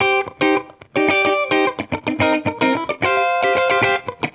Index of /musicradar/sampled-funk-soul-samples/110bpm/Guitar
SSF_TeleGuitarProc1_110C.wav